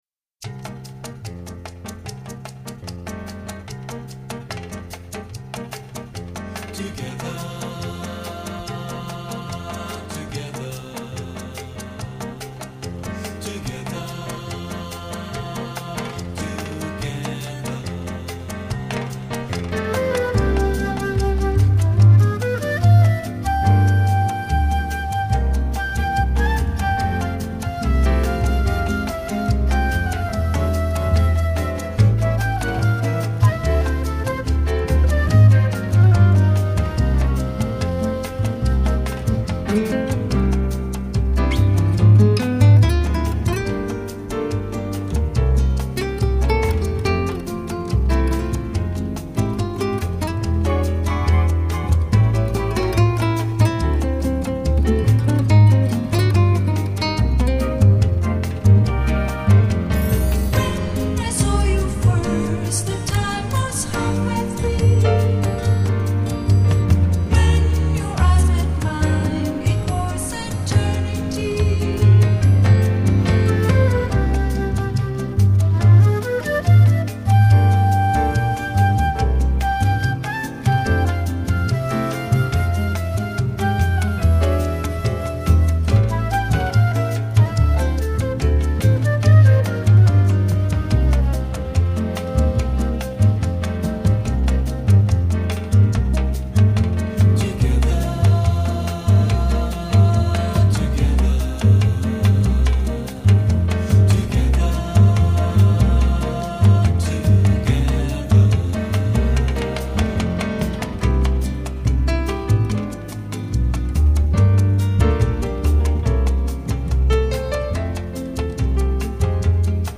立体声WAV整轨+CUE/立体声WAV分轨